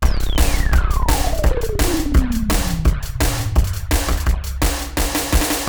SWEEP BEAT 2.wav